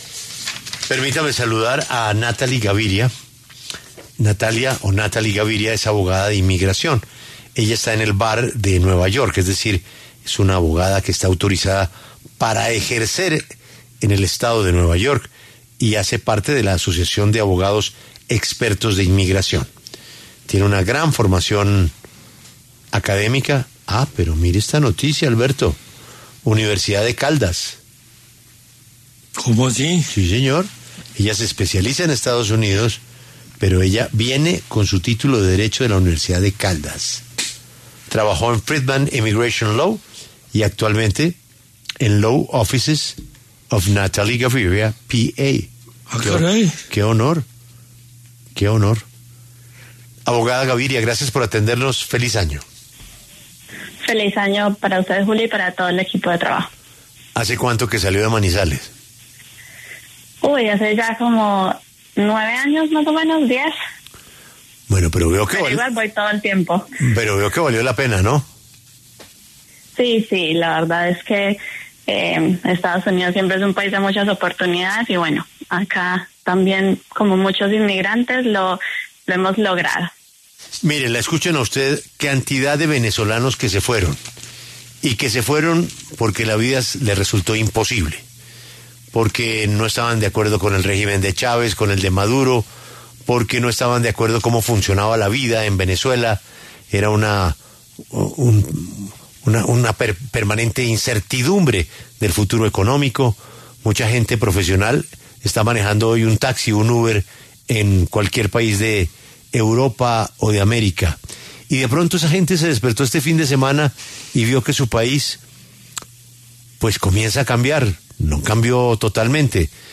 abogada especializada en inmigración